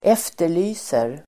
Uttal: [²'ef:ter_ly:ser]